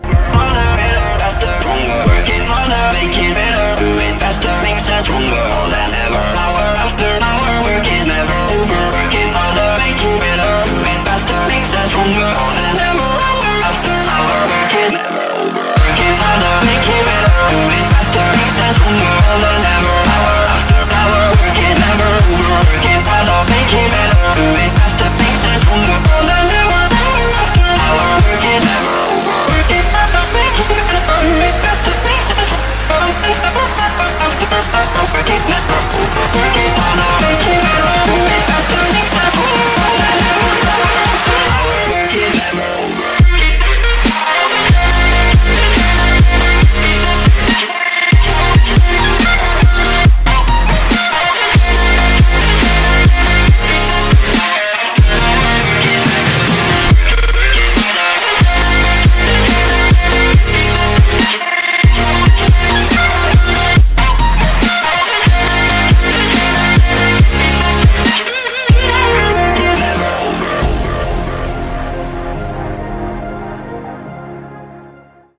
Стиль: trap, dubstep Ура!